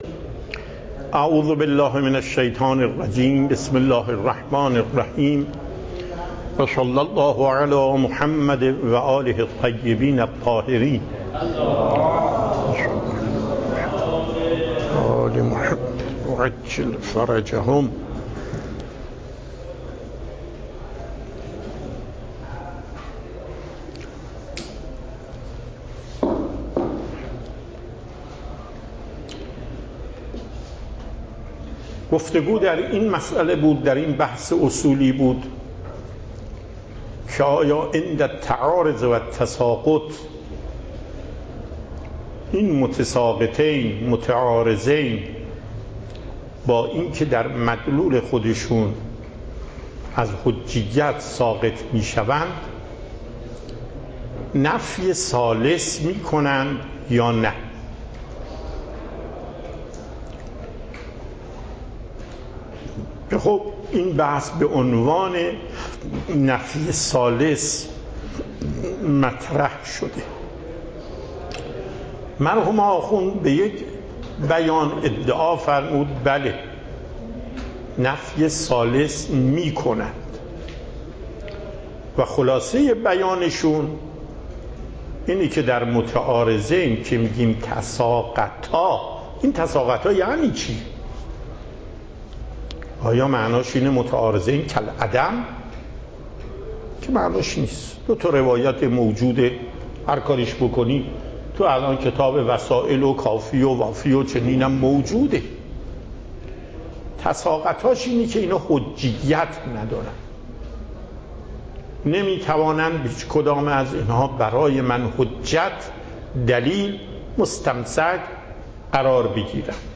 پخش صوت درس
درس اصول آیت الله محقق داماد